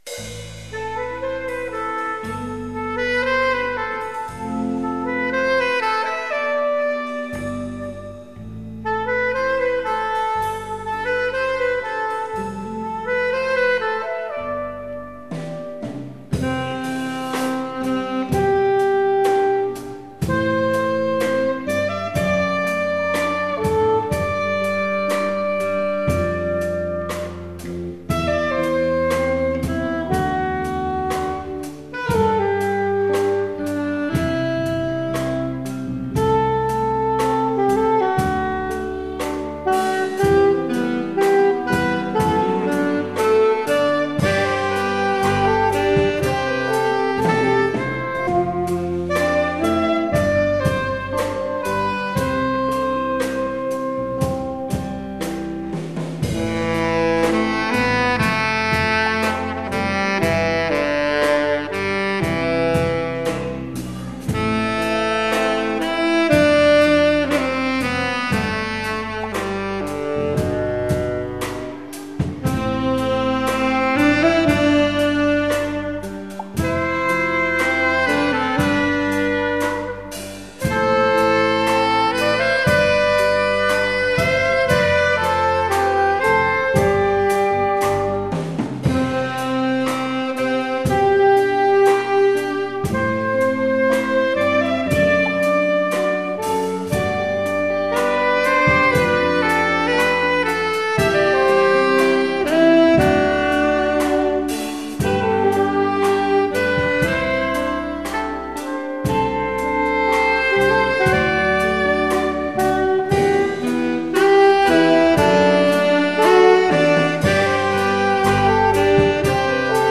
Jazzband